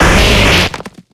Audio / SE / Cries / GOLEM.ogg